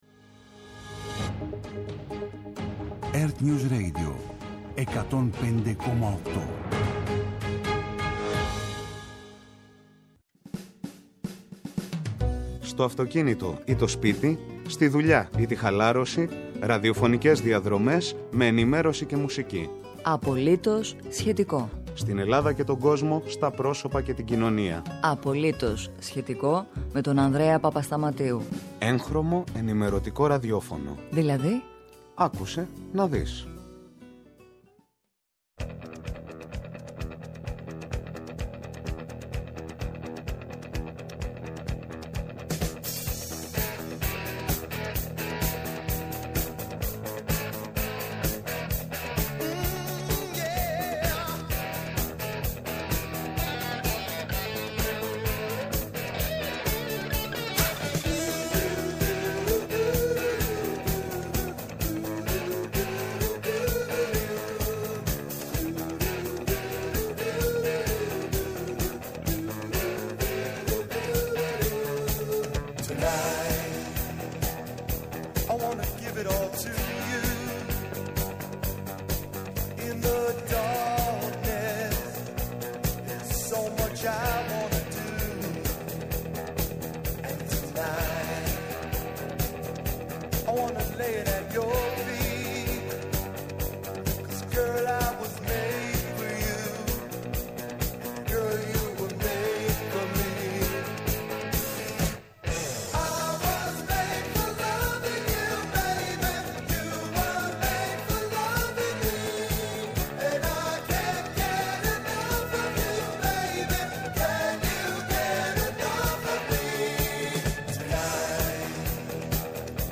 ·-Η Νατάσα Θωμά Πρόεδρος της Κοινότητας Μικροσπηλιάς στα Κεντρικά Τζουμέρκα (ειδικό κλιμάκιο γεωλόγων σήμερα στην περιοχή, εξετάζει τα μεγάλα ρήγματα που προκλήθηκαν από κατολισθήσεις λόγω της κακοκαιρίας)